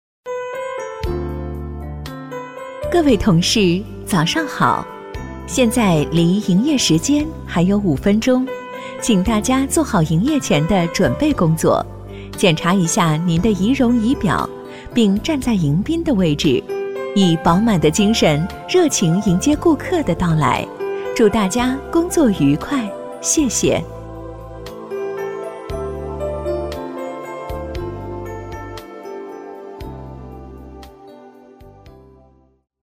女18-广播提示【商场广播-营业前温馨广播】
女18-广播提示【商场广播-营业前温馨广播】.mp3